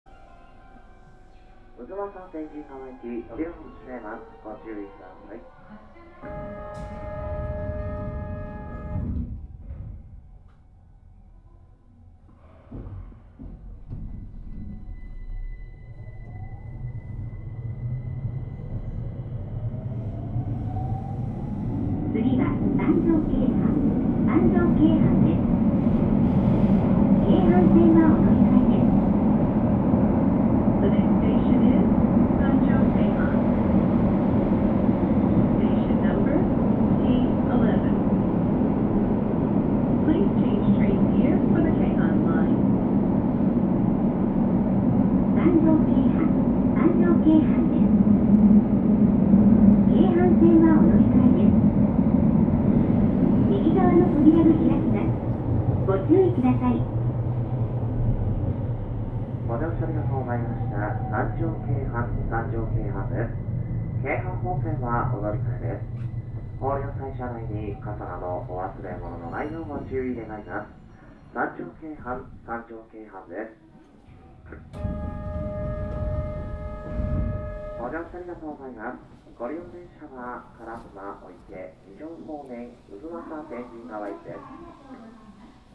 走行音
界磁添加励磁制御車
録音区間：東山～三条京阪(お持ち帰り)